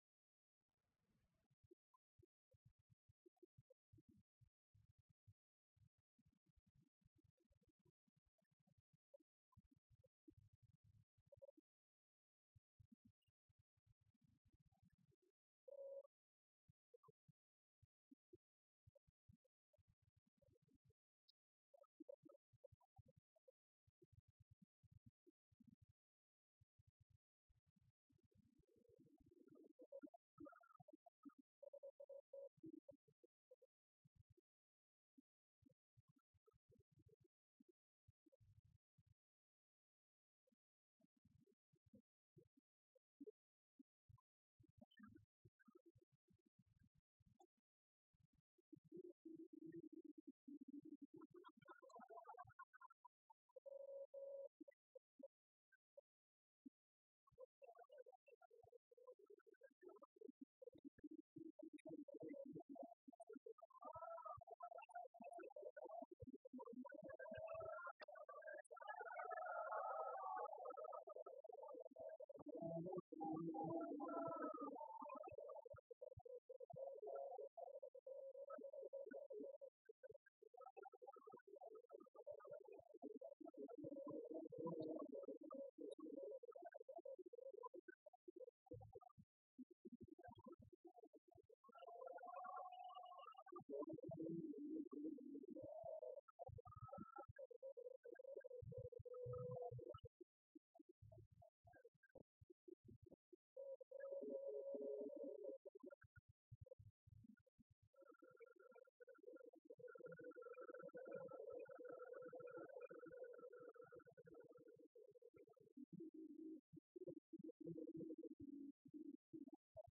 شب اربعین هیات رایه العباس (ع)
شب اربعین هیات رایه العباس (ع) با مداحی حاج محمود کریمی برگزار گردید.